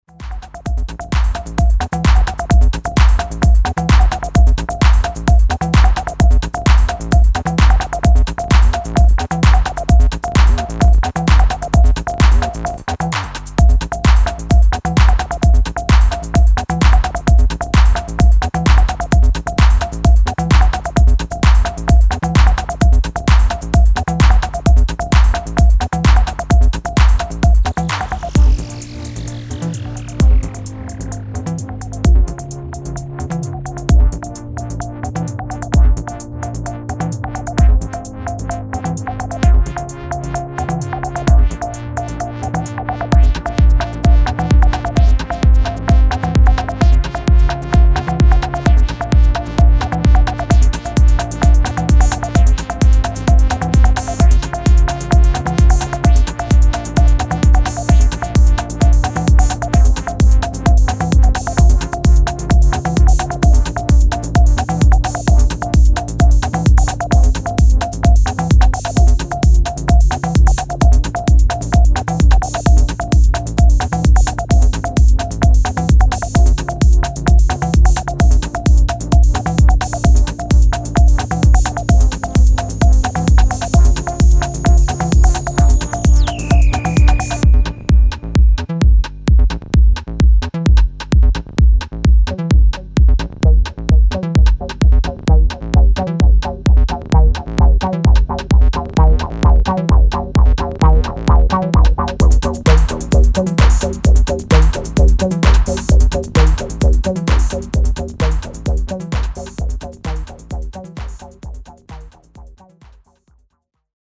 solid acid tracks